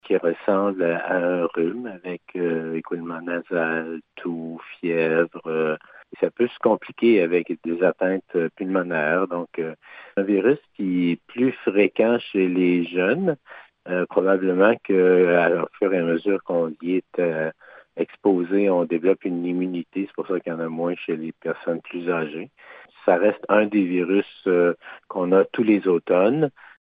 Le directeur de la santé publique, Dr Yv Bonnier-Viger, explique que les symptômes du VRS ressemblent à ceux du rhume.